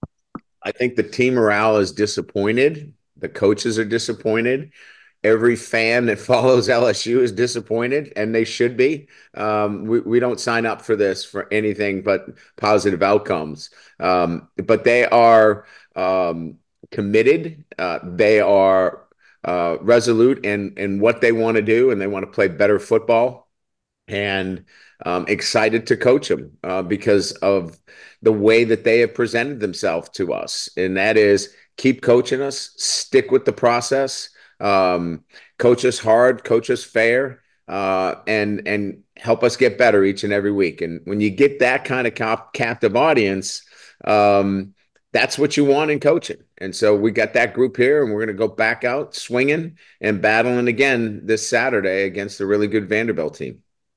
LSU head coach Brian Kelly discusses morale of the team post Florida loss:
Kelly-team-morale.wav